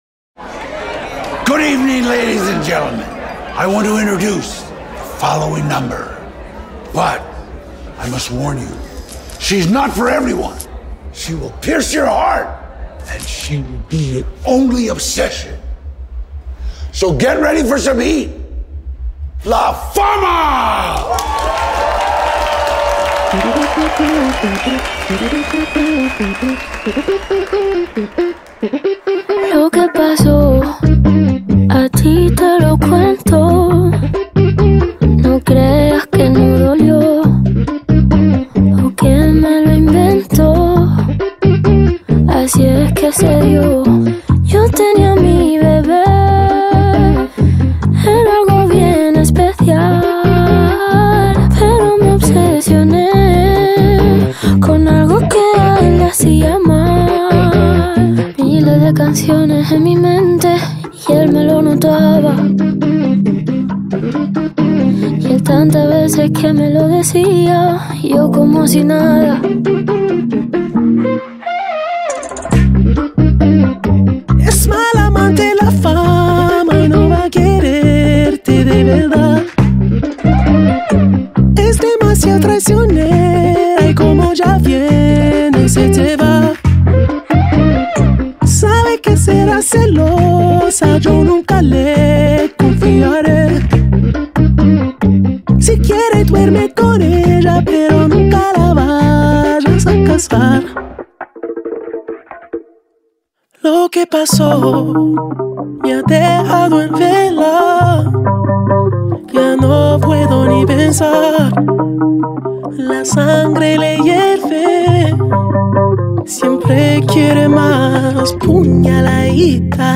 قطعات احساسی و هنری